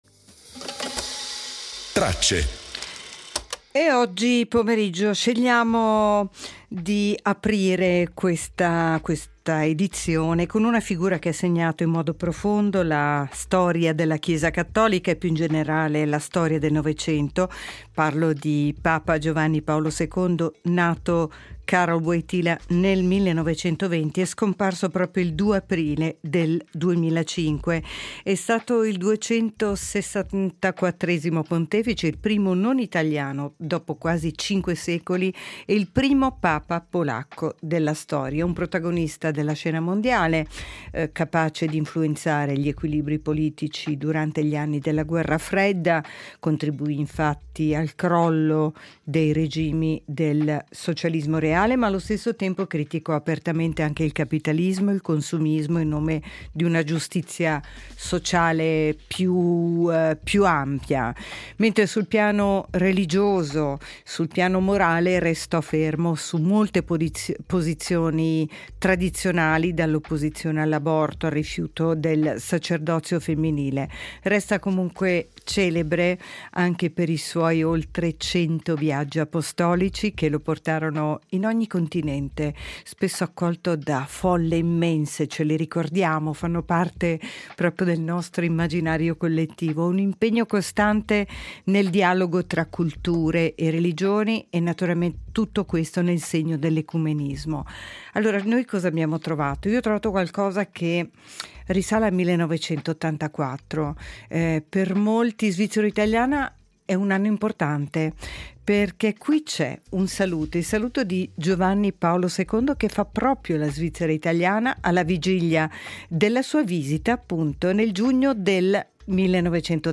Quattro frammenti d’archivio raccontano il nostro tempo attraverso voci lontane ma ancora vive: il saluto del mondo a papa Giovanni Paolo II, la nascita dei cromosomi artificiali che aprirono nuove strade alla genetica, l’antica ricerca dell’elisir di lunga vita e il ritratto dei mestieri di ieri, specchio di un Paese che cambiava.